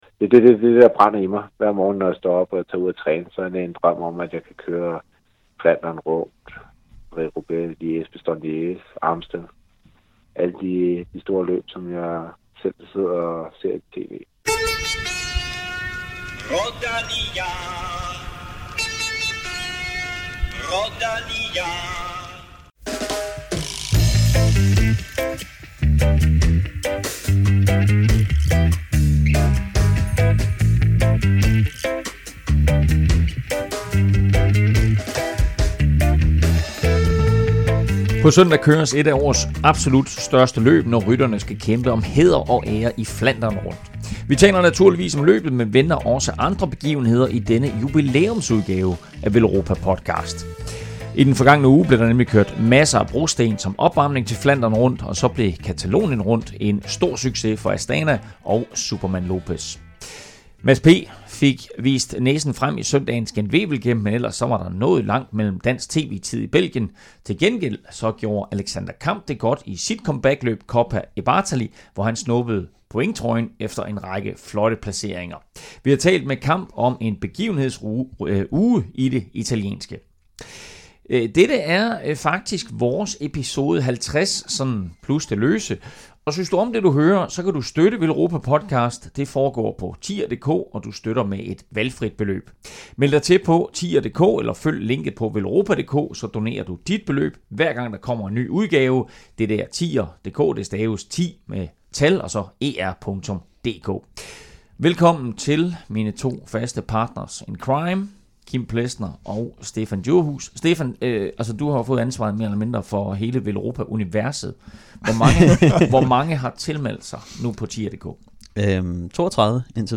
et hudløst ærligt interview